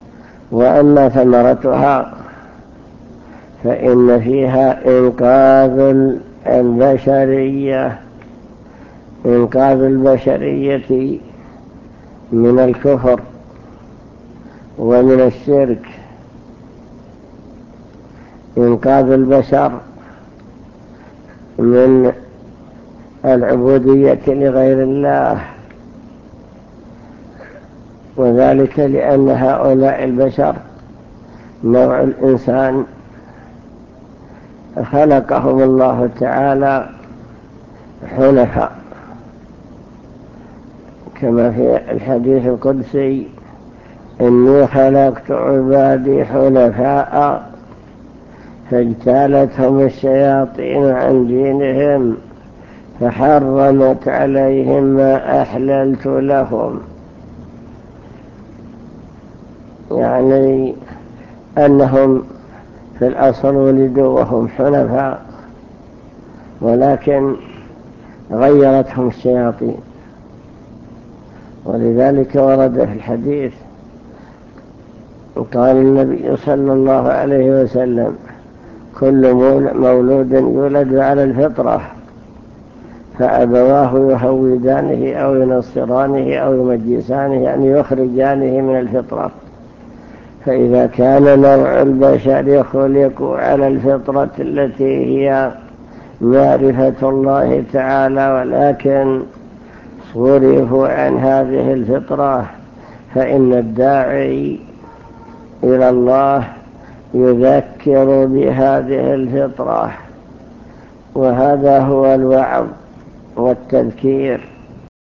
المكتبة الصوتية  تسجيلات - لقاءات  لقاء مع الشيخ بمكتب الجاليات